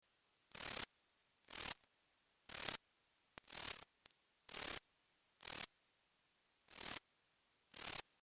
Rhacocleis germanica
Per rendere udibile il campione audio, registrato con Ultramic 250, la registrazione è stata sottocampionata e amplificata: il timbro risultante è più smorto e meno stridente dell'originale.
To make it audible, the audio sample, recorded with Ultramic 250, was downsampled and amplified: the resulting timbre is duller and less screeching than the original.
Il canto consiste in fruscii/ronzii (versi) di 0,2-0,7 sec, che sono ripetuti a intervalli regolari di circa 1-3 sec.
The song consists of rustling/buzzing sounds (echemes) of 0,2-0,7s, repeated fairly regular at intervals of about 1-3s.
Rhacocleis_germanica.mp3